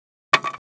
Index of /traerlab/AnalogousNonSpeech/assets/stimuli_demos/jittered_impacts/small_styrofoam_longthin_plank